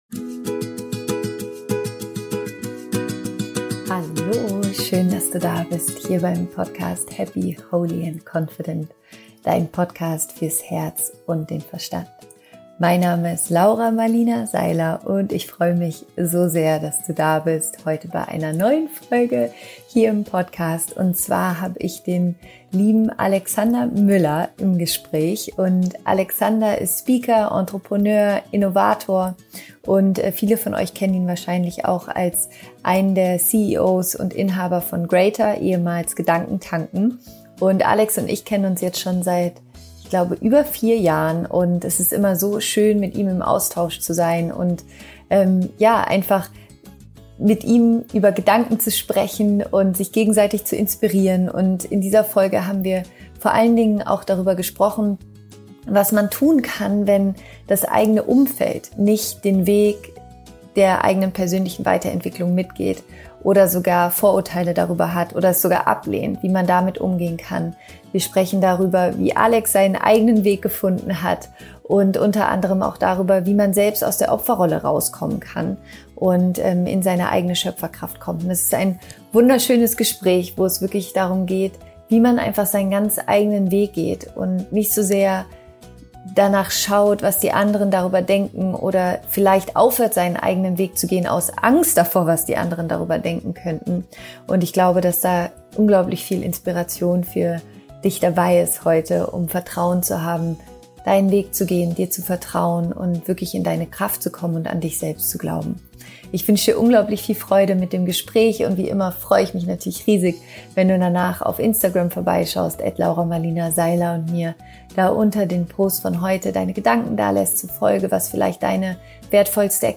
Viel Spaß mit diesem Interview!